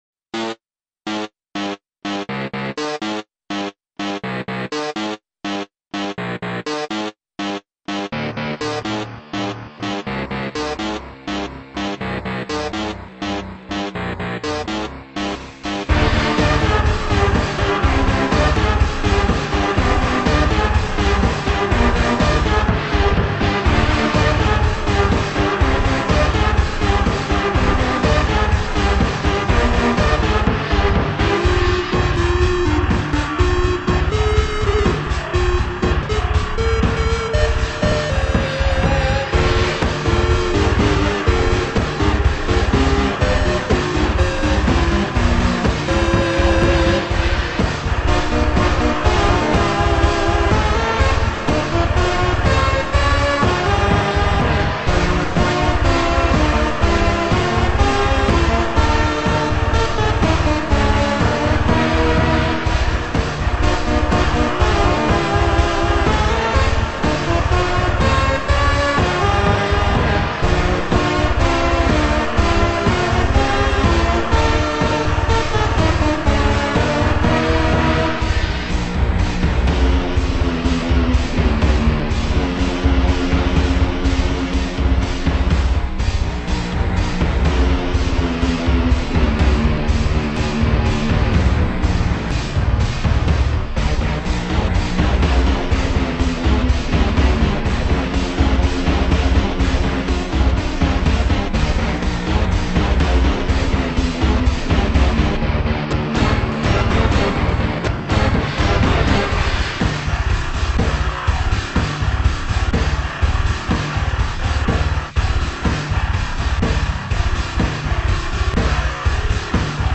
“every second beat” version